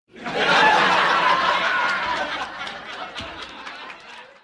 Funny Laugh Track